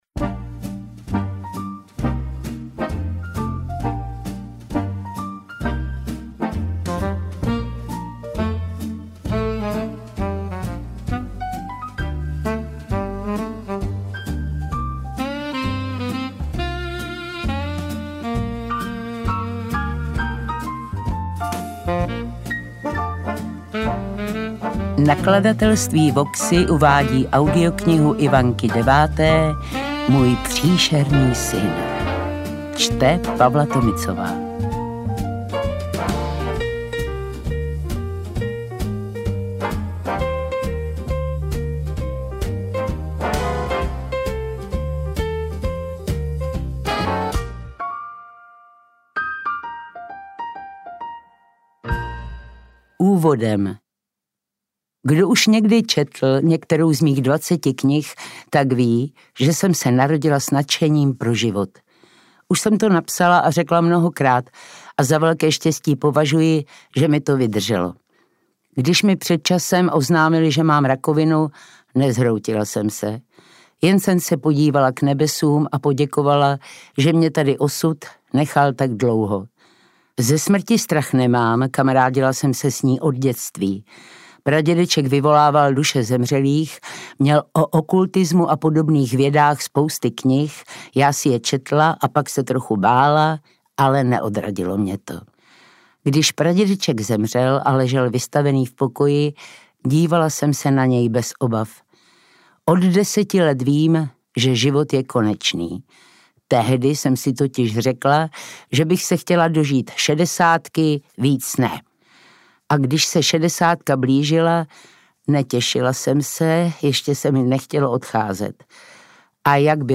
Interpret:  Pavla Tomicová
AudioKniha ke stažení, 33 x mp3, délka 4 hod. 30 min., velikost 244,8 MB, česky